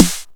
SNARE19.wav